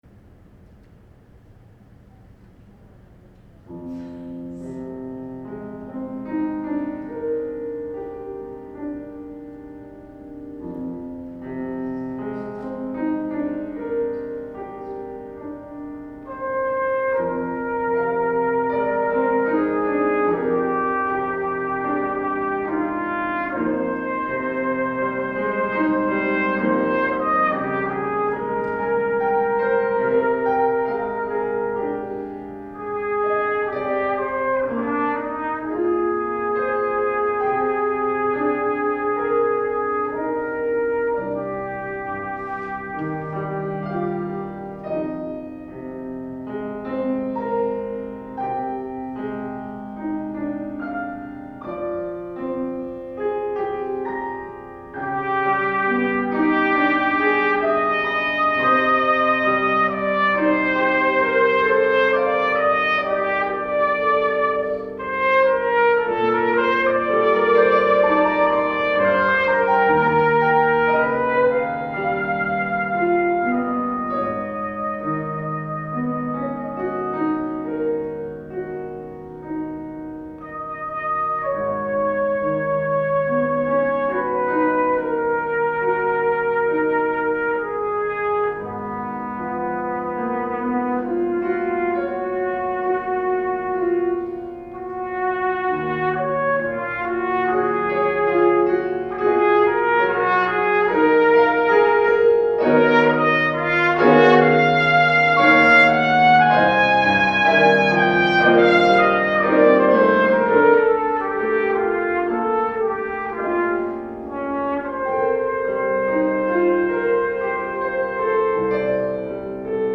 II. Andante Espressivo